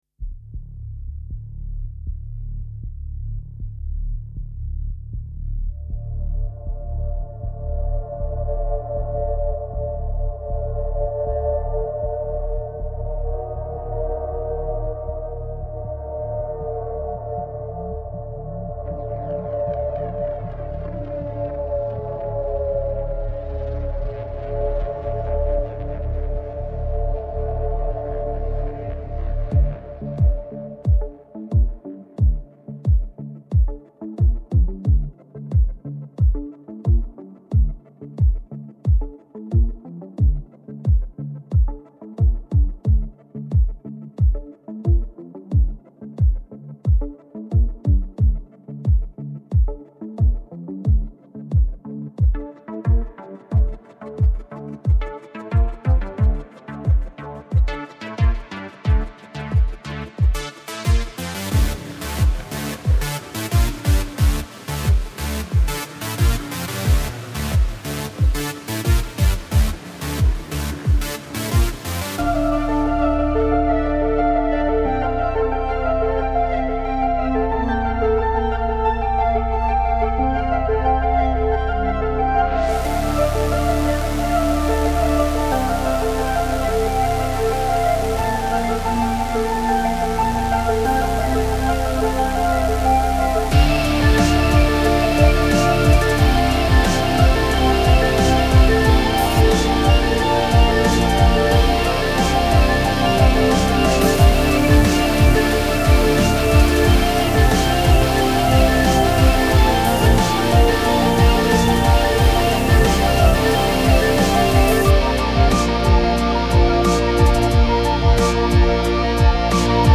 BPM 90
Hybrid Orchester
Genre Imagefilm , Trailer